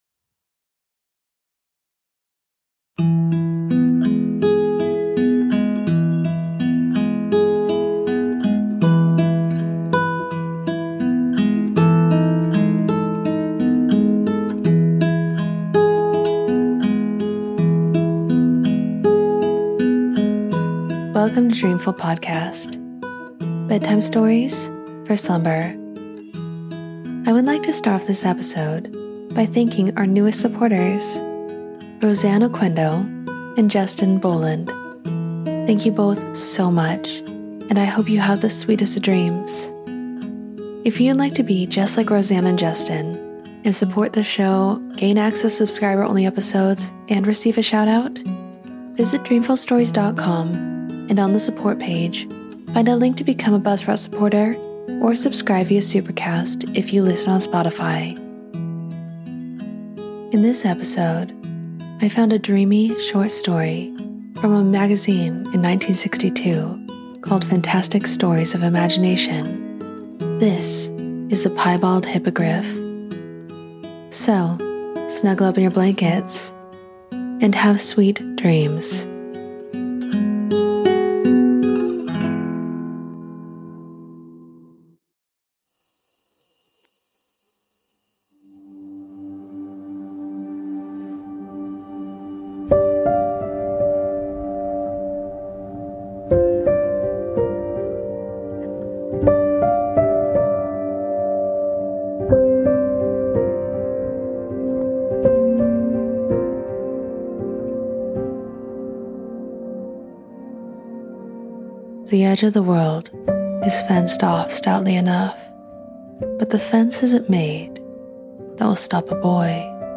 Fall asleep to a dreamy short story from 1962’s Fantastic Stories of Imagination: The Piebald Hippogriff. A boy named Johnny reaches the world’s edge, looks past the granite lip into an endless blue, and finds islands adrift, star-flowers nodding, and a herd of hippogriffs turning the air into a playground.